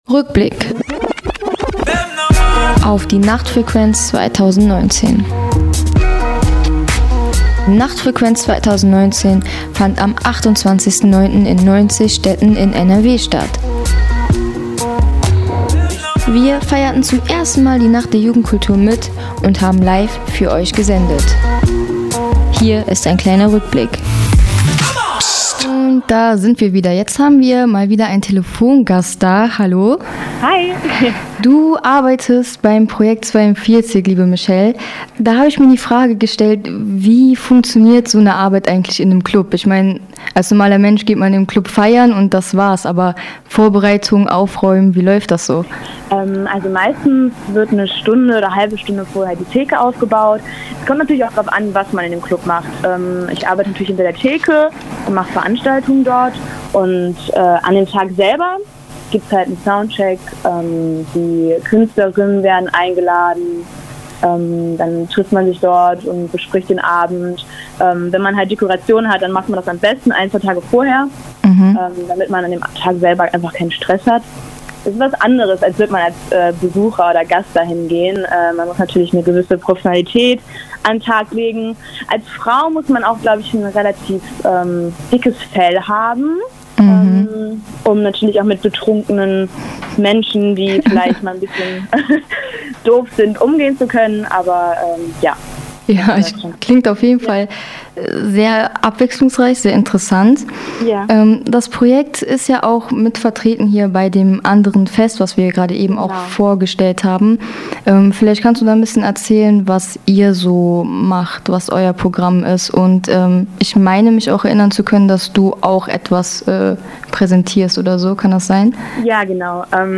Für Euch haben wir jetzt die besten Ausschnitte unserer Live-Sendung während dieser „langen Nacht der Jugendkultur“ herausgesucht.